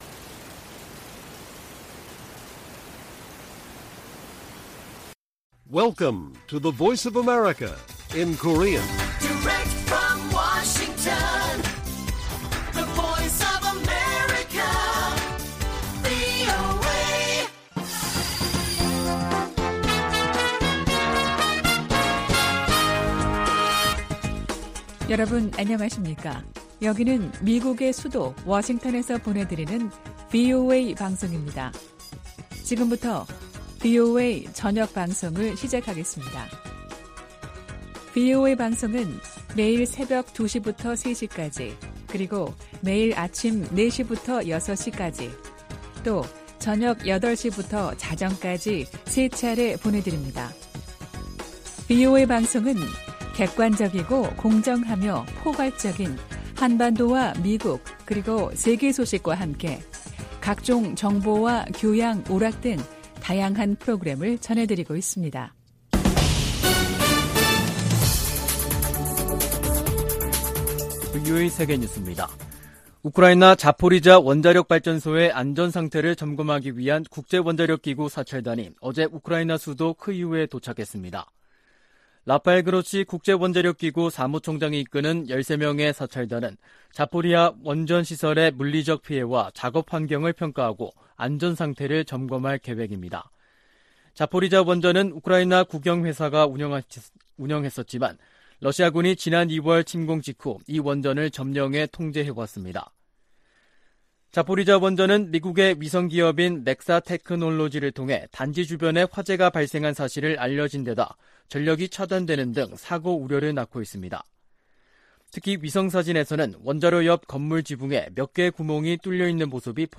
VOA 한국어 간판 뉴스 프로그램 '뉴스 투데이', 2022년 8월 30일 1부 방송입니다. 미 국무부는 북한과의 대화 필요성을 재확인하면서도 도발에 대응하고 제재를 이행하겠다는 의지를 강조했습니다. 권영세 한국 통일부 장관은 ‘담대한 구상’ 제안에 북한이 호응할 것을 거듭 촉구했습니다. 미 의회 내에서는 북한이 핵실험을 강행할 경우 북한과 거래하는 중국 은행에 ‘세컨더리 제재’를 가해야 한다는 목소리가 높습니다.